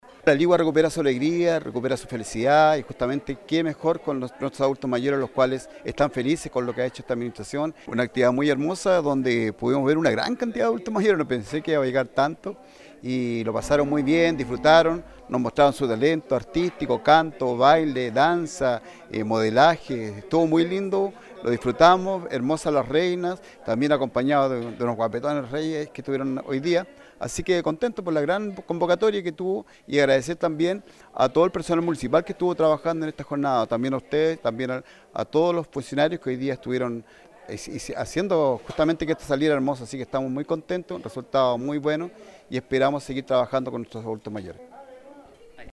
Gala-Personas-Mayores-La-Ligua-2022-–-alcalde-Pallares.mp3